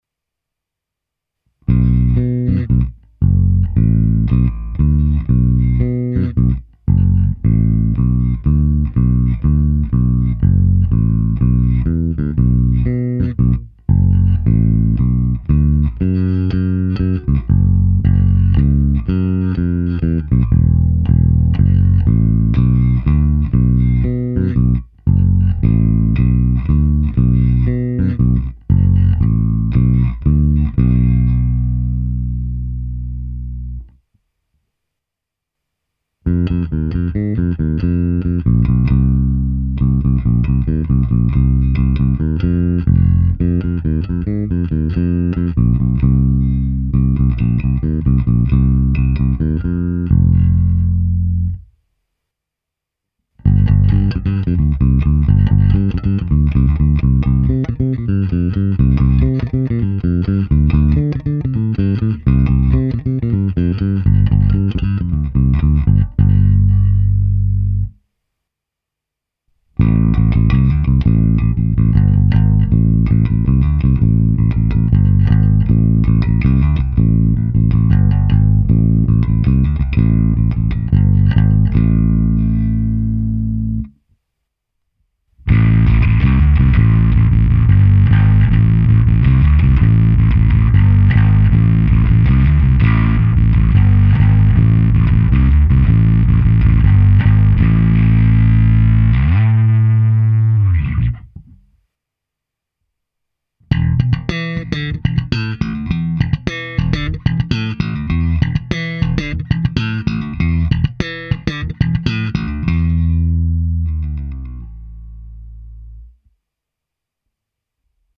Ukázka přes Darkglass Alpha Omega Ultra se zapnutou simulací aparátu a kompresor TC Electronic SpectraComp. Hráno na oba snímače, přičemž basy a výšky jsem na aktivní elektronice malinko přidal.